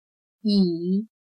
椅\yǐ\Silla; sonar; ruido; eco